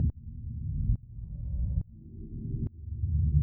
Index of /musicradar/sidechained-samples/140bpm